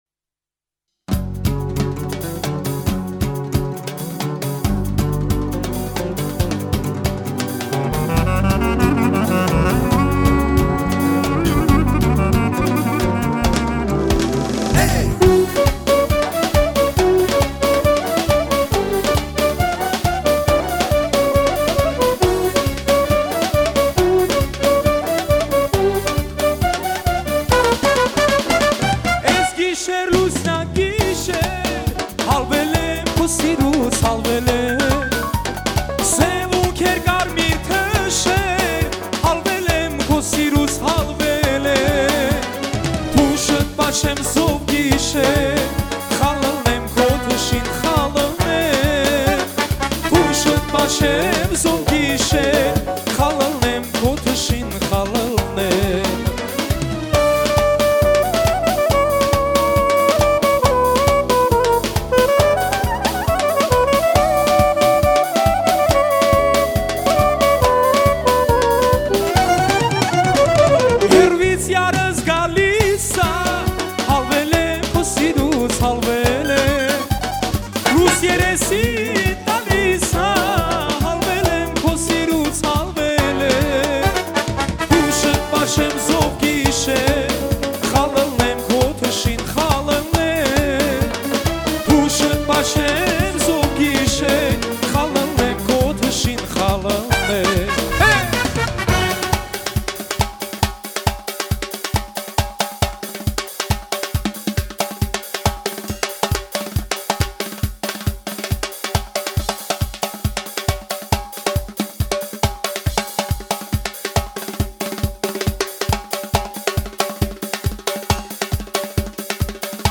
Трек размещён в разделе Русские песни / Армянские песни.